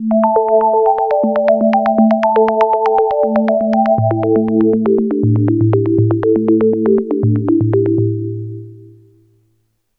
Novation Peak – Klangbeispiele
novation_peak_test__arpeggio_2.mp3